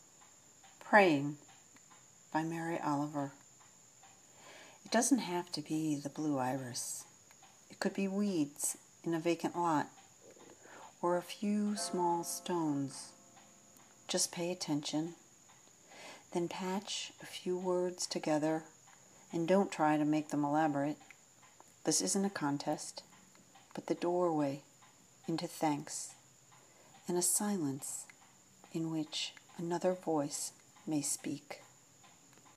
i knew, too, right away, just where i wanted to read it, the poem about prayer — amid my late-summer garden, so the words of mary oliver would be enfolded, would be punctuated, with the sounds of this summer drawing to a close: the few cicada still buzz-sawing, the blue jay who squawks, even the wind rustling through the boughs of the willow.
my friend asked for a poem. i sent her the pulse of my heart, and a sound-swatch of the late summer garden.
mary-oliver-22praying22.m4a